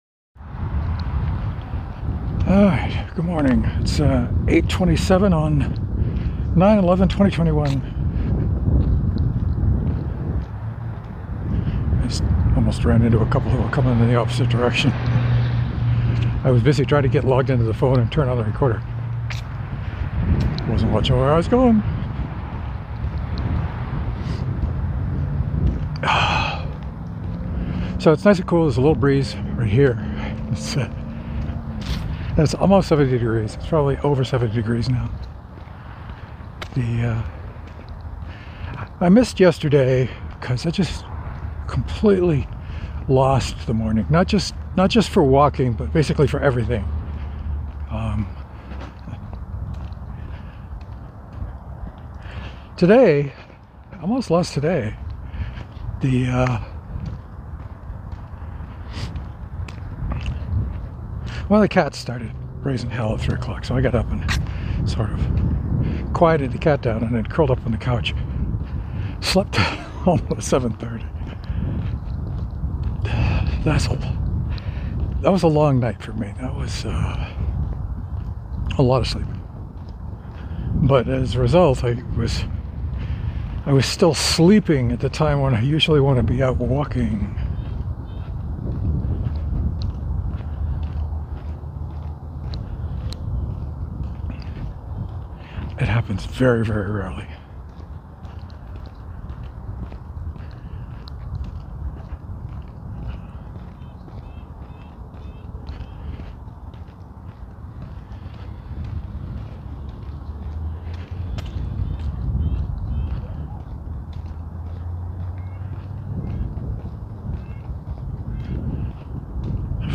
But got my butt out onto the sidewalk anyway. Lot of walking, not so much talking.